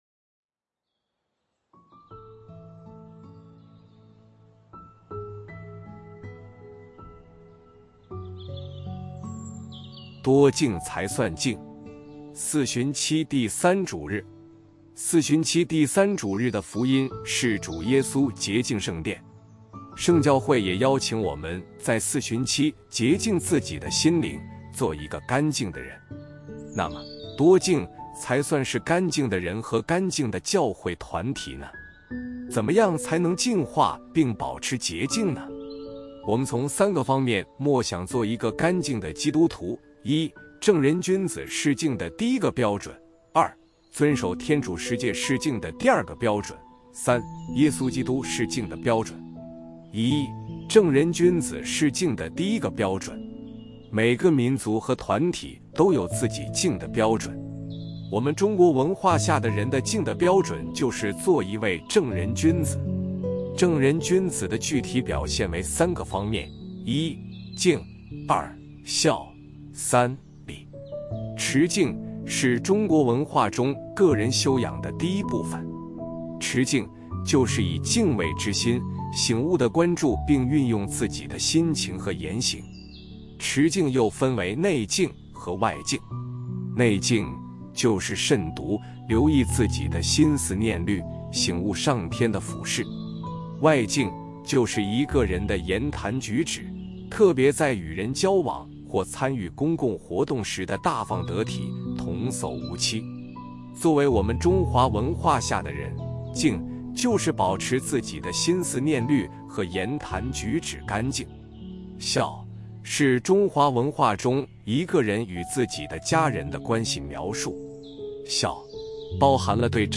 【主日证道】| 多净才算净（乙-四旬期第3主日）